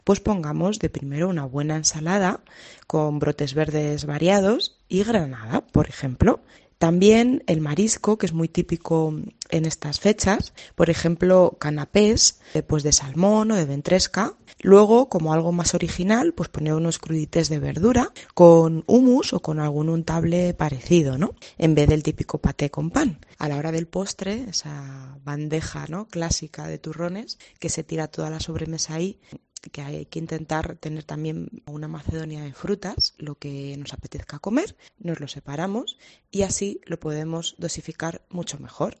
nutricionista